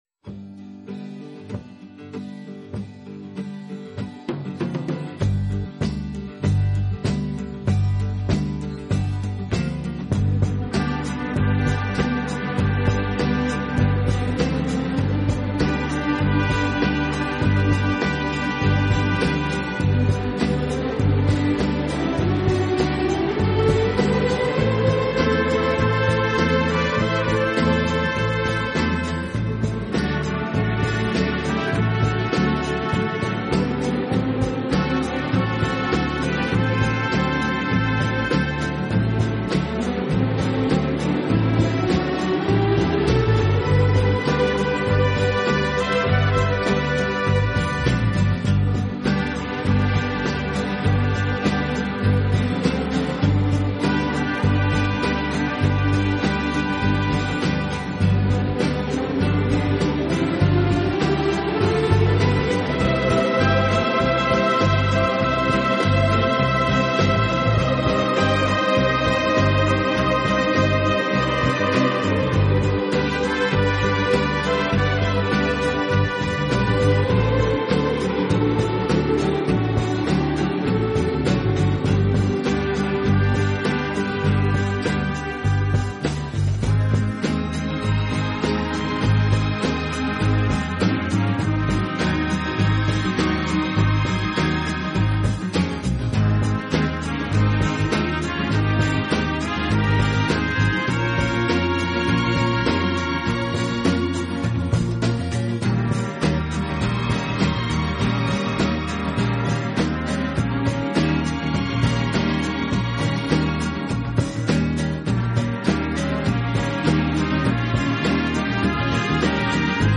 【轻音乐】
此外，这个乐队还配置了一支训练有素，和声优美的伴唱合唱队。